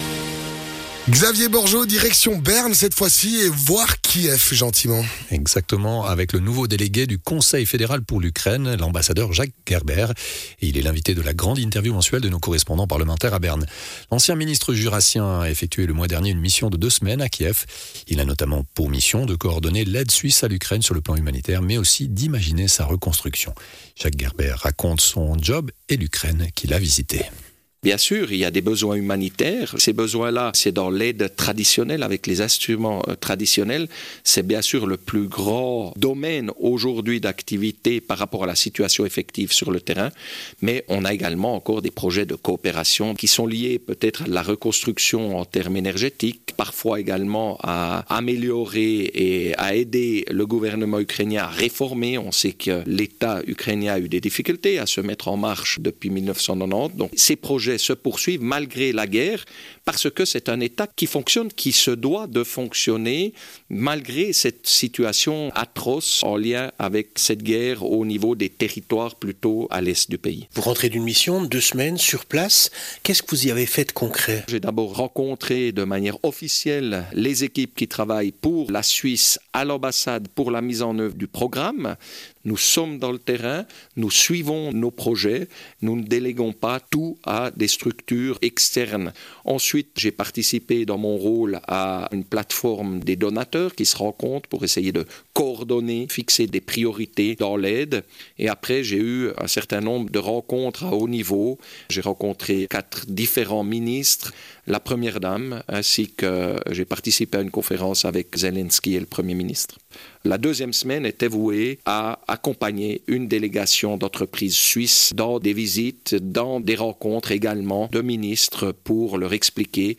Grande interview à Berne: l'Ambassadeur Jacques Gerber, nouveau délégué du Conseil fédéral pour l'Ukraine
Intervenant(e) : Jacques Gerber, nouveau délégué du Conseil fédéral pour l’Ukraine